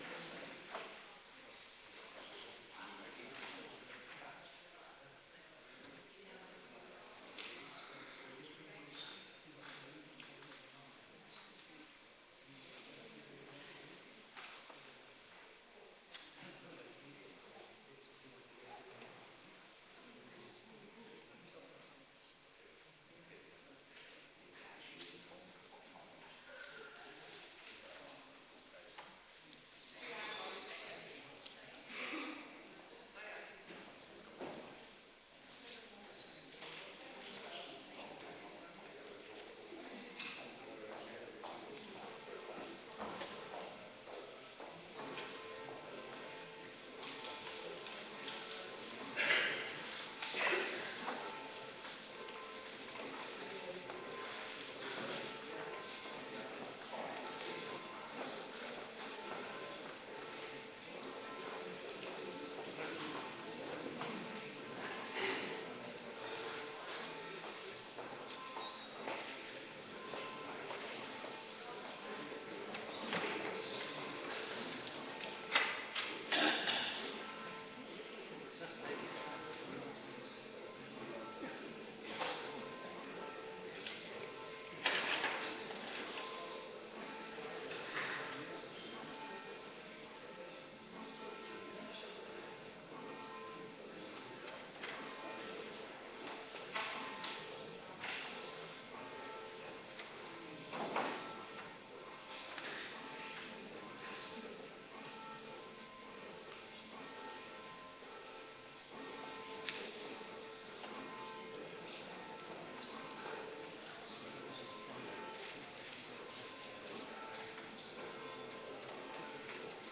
Adventkerk Zondag week 14